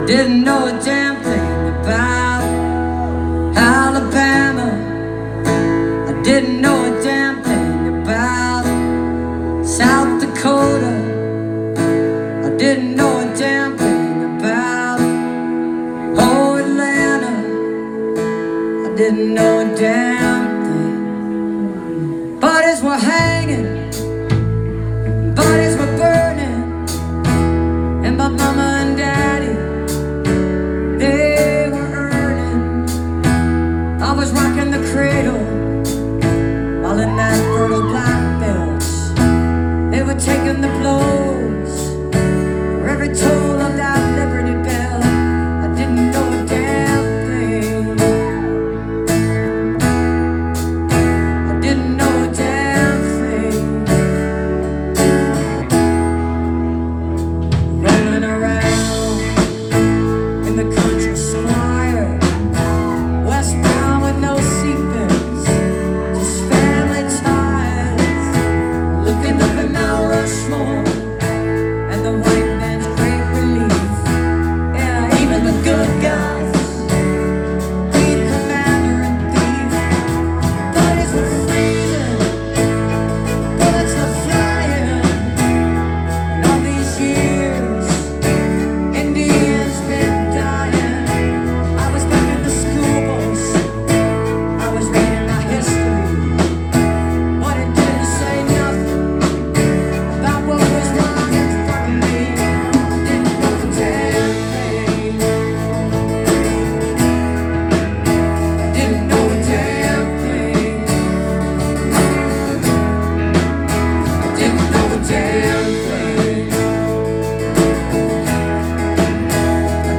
(incomplete capture of a full show)
(captured from a youtube)